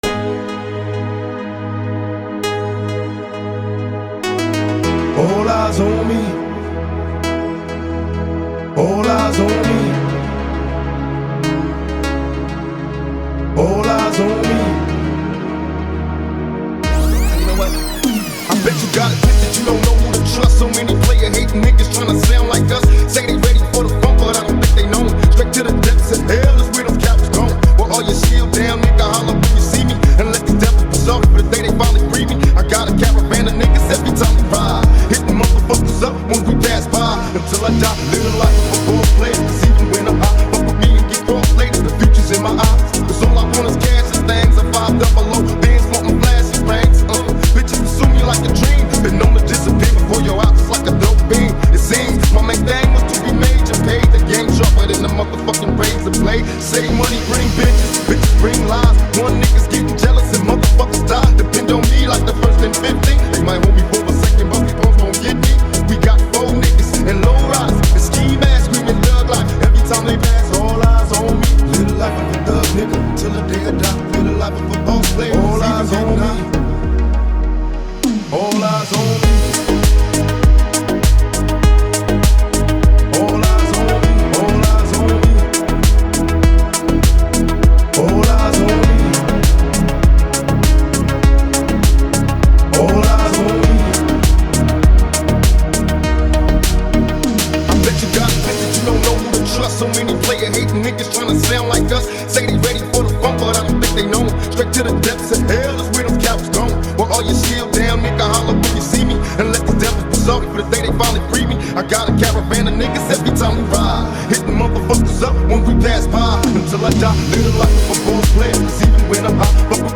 Жанр: Deep House